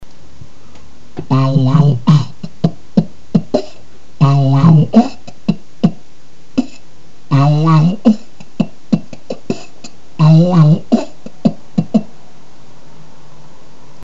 делать дома было нечего, и вот решил заняться битбоксом biggrin
у первой записи качество хромает что-то, я не совсем понял: кроме ударных там ещё какой-то звук был, на шипение похож, без обид, но мне просто интересно, что пытался сделать? suspect